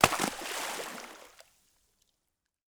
SPLASH_Small_02_mono.wav